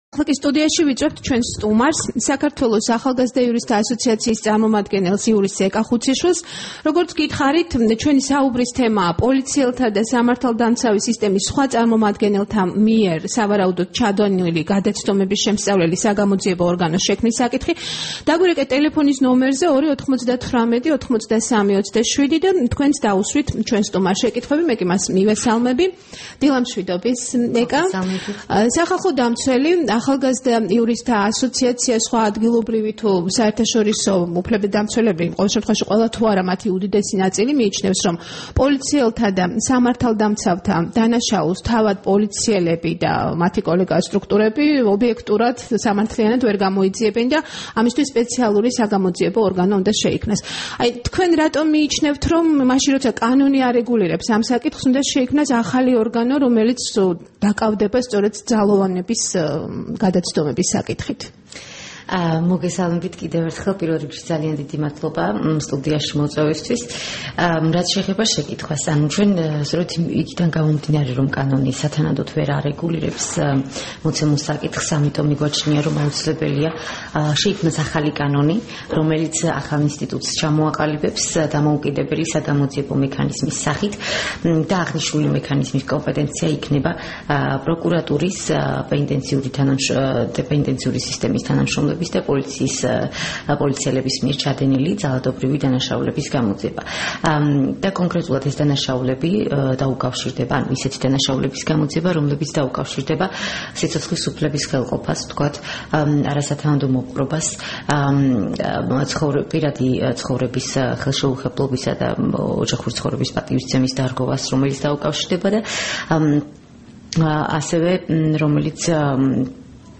რადიო თავისუფლების დილის გადაცემის სტუმარი იყო
საუბარი